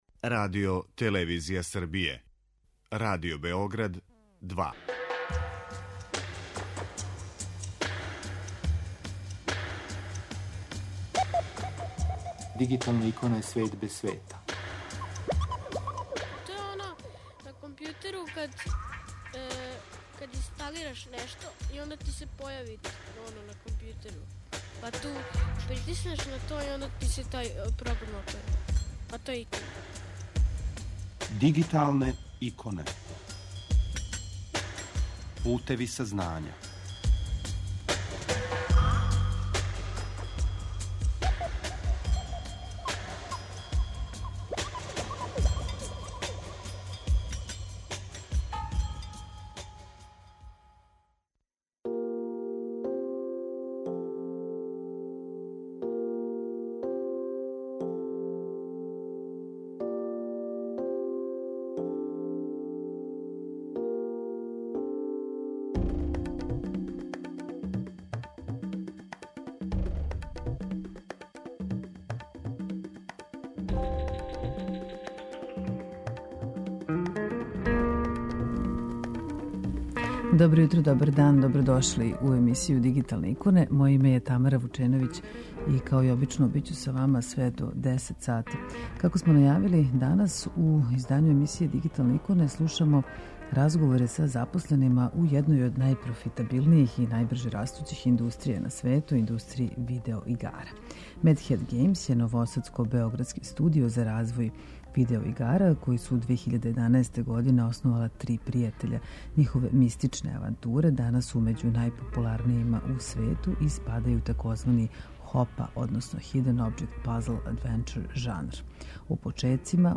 У овом издању емисије 'Дигиталне иконе' слушамо разговоре са запосленима у једној од најпрофитабилнијих и најбрже растућих индустрија на свету - индустрији видео-игара.